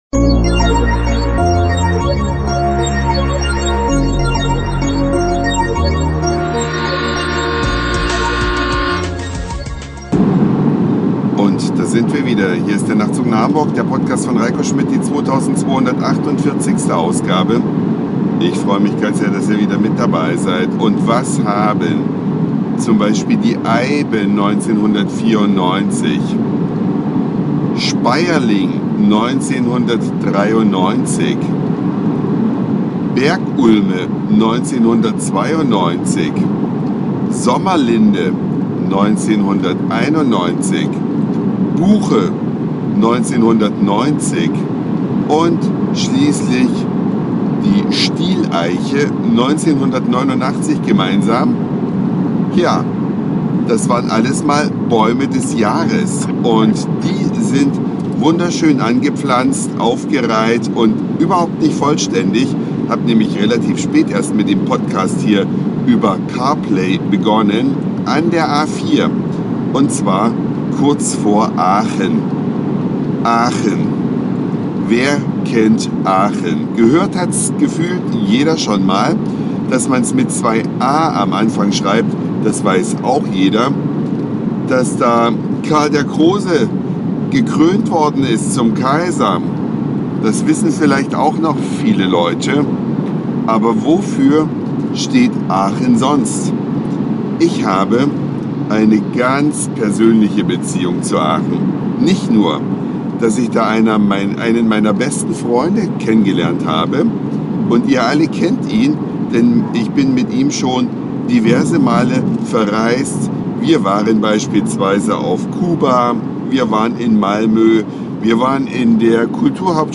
Eine Reise durch die Vielfalt aus Satire, Informationen, Soundseeing und Audioblog.
auf dem Weg nach Aachen, Gedanken auf der A4